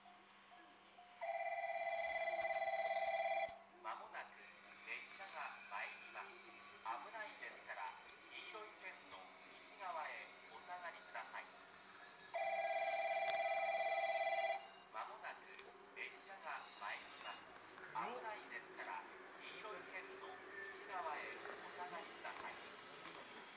この駅では接近放送が設置されています。
３番のりば日豊本線
接近放送普通　国分行き接近放送です。